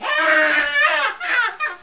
c_mnky_dead.wav